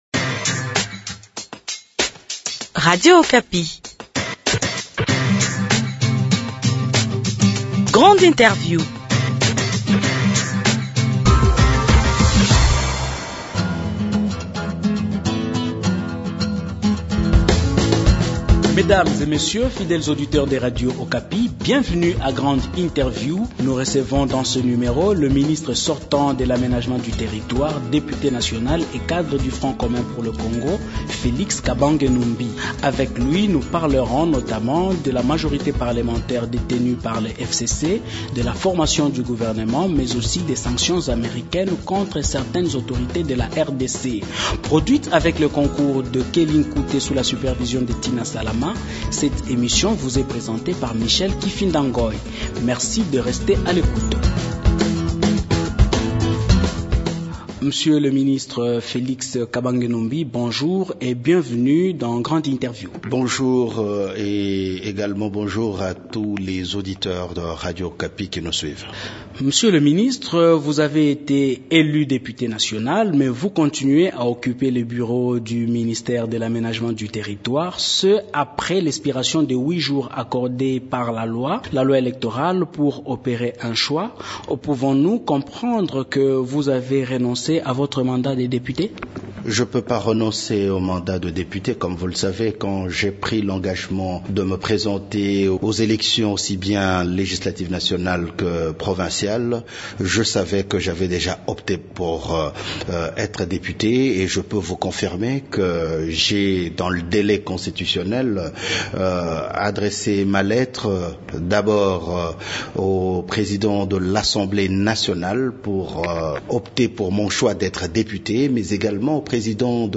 Felix Kabange Numbi, ministre sortant de l’aménagement du territoire, député national et membre du comité stratégique du Front Commun pour le Congo, est l’invite de Grande Interview.